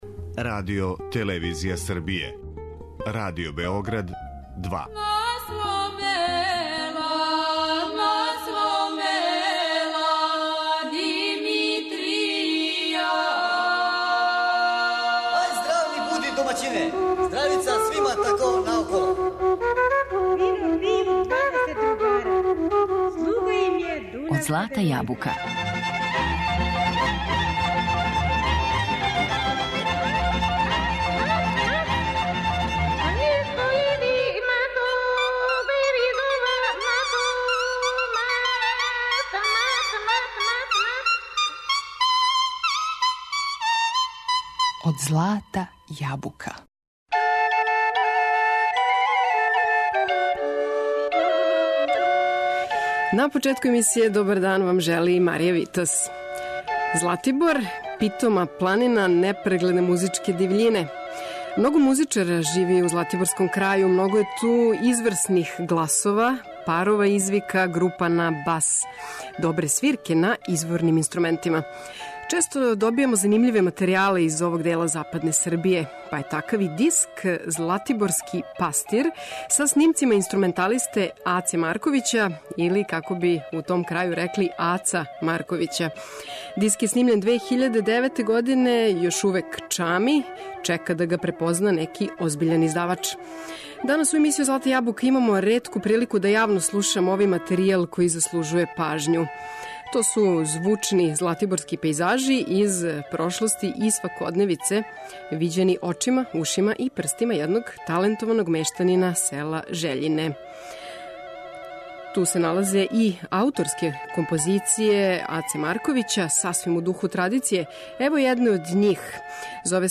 посвећено и умешно негује свирку на традиционалним инструментима - двојницама и фрули.